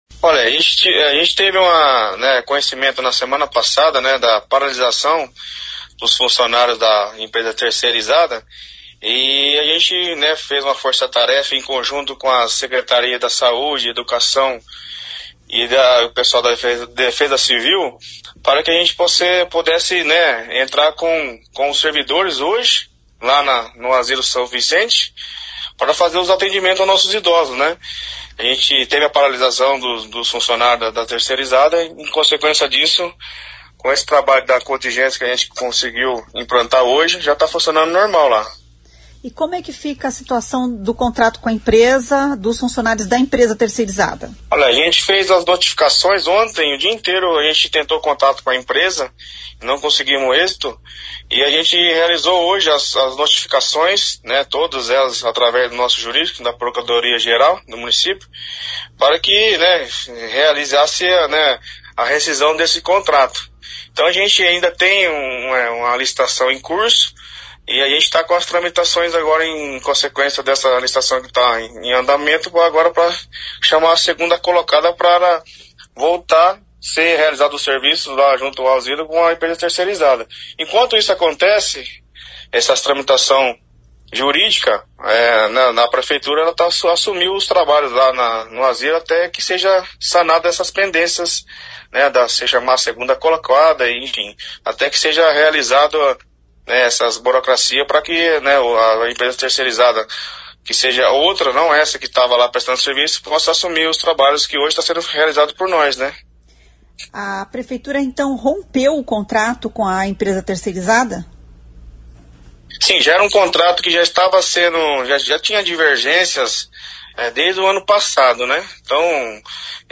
Ouça o que diz o secretário: